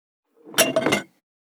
204,食器,グラス,コップ,工具,小物,雑貨,コトン,トン,ゴト,ポン,ガシャン,ドスン,ストン,カチ,タン,バタン,スッ,サッ,コン,ペタ,パタ,チョン,コス,カラン,ドン,チャリン,効果音,環境音,BGM,
コップ効果音物を置く